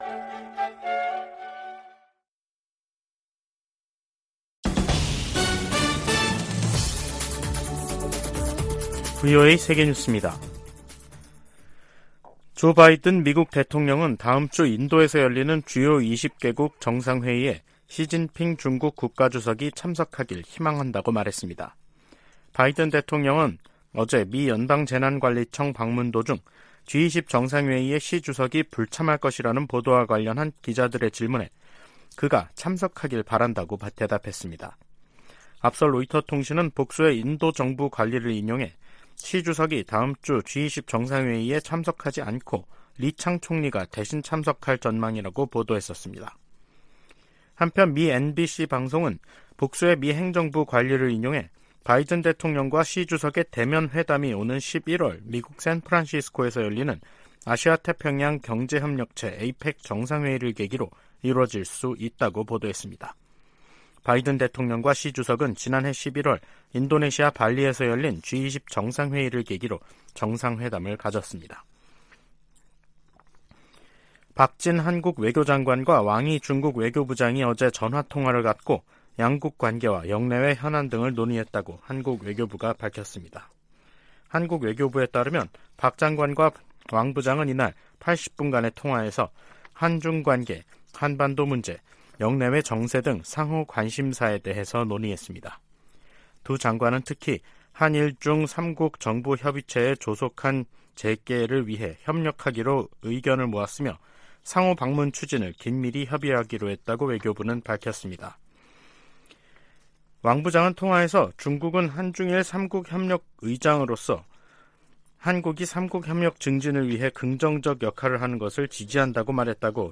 VOA 한국어 간판 뉴스 프로그램 '뉴스 투데이', 2023년 9월 1일 2부 방송입니다. 미국 재무부가 북한의 대량살상무기(WMD)와 탄도미사일 개발에 자금조달을 해온 북한인과 러시아인들을 제재했습니다. 미 의회 산하 중국위원회가 유엔 인권과 난민 기구에 서한을 보내 중국 내 탈북민 북송을 막도록 개입을 요청했습니다. 미 국방부가 북한에 러시아와의 무기 거래 협상 중단을 촉구했습니다.